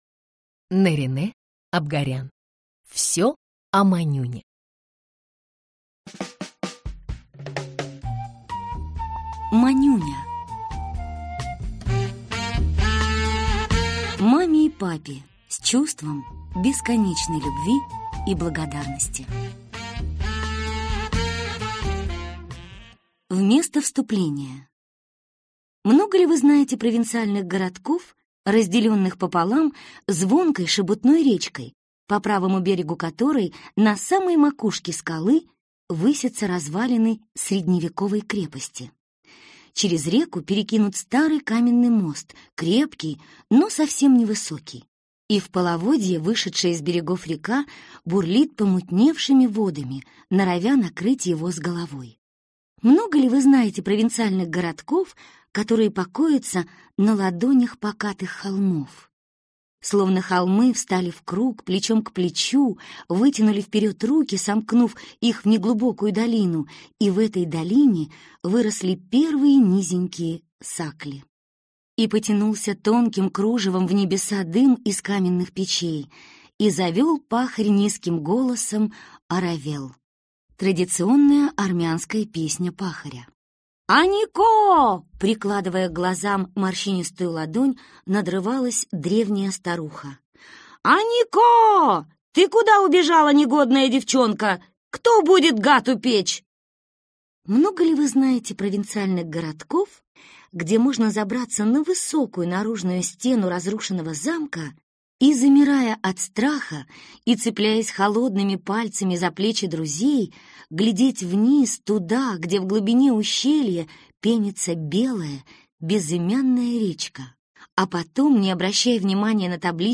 Аудиокнига Всё о Манюне (сборник) | Библиотека аудиокниг